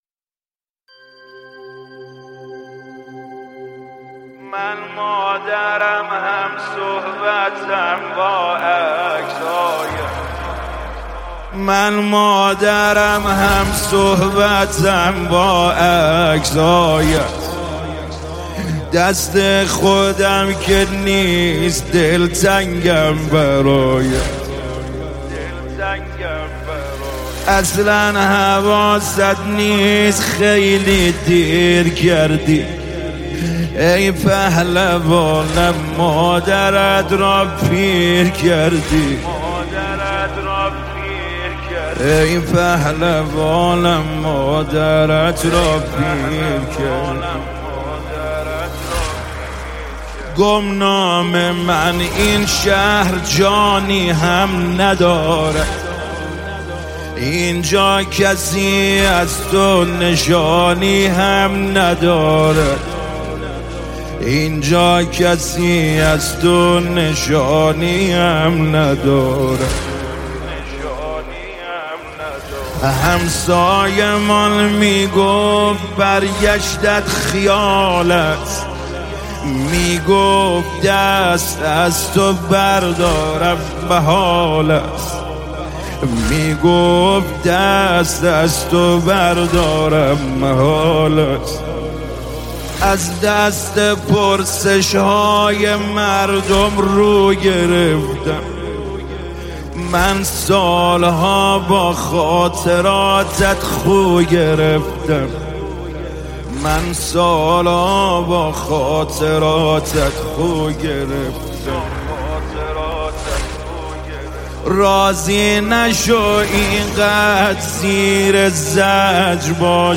مذهبی
مداحی استودیویی